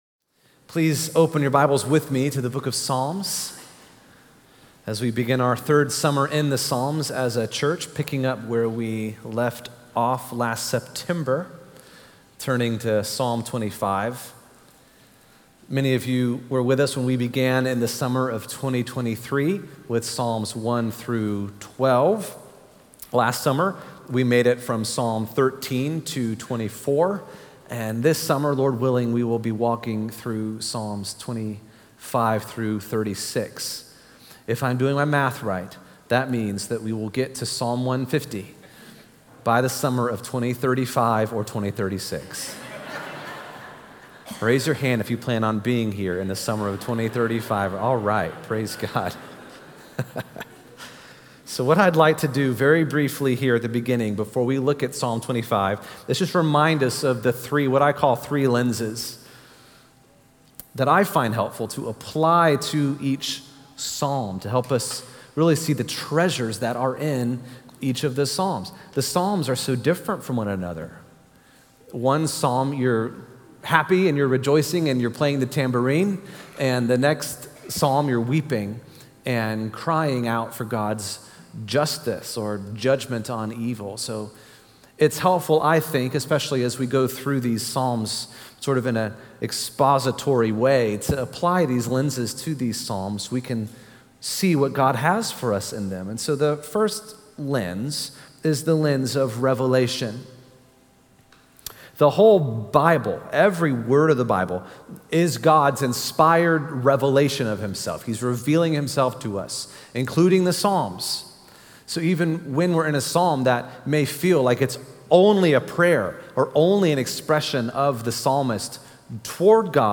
6.15-sermon-audio-2.mp3